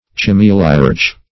Search Result for " cimeliarch" : The Collaborative International Dictionary of English v.0.48: Cimeliarch \Ci*me"li*arch\, n. [L. cimeliarcha, Gr.